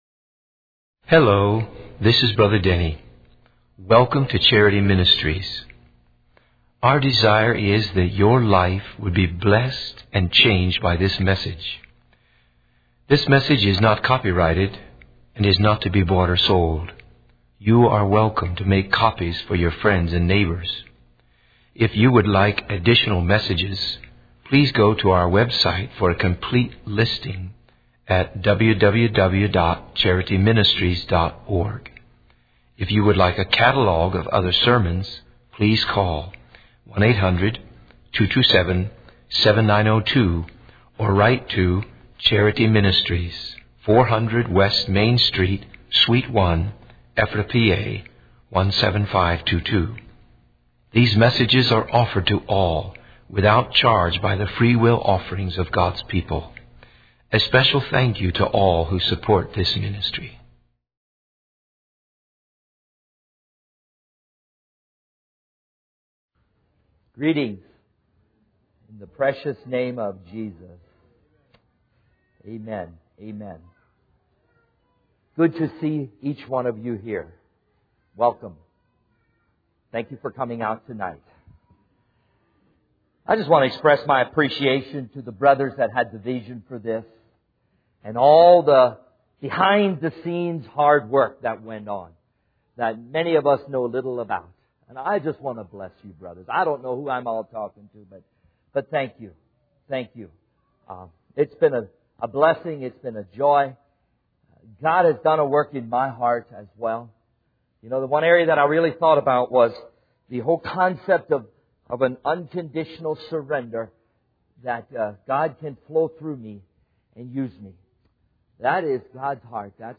In this sermon on John 15, the preacher begins by expressing his desire for the congregation to not just be challenged, but to be truly changed and live in the commitments and reality of what God has done in their hearts.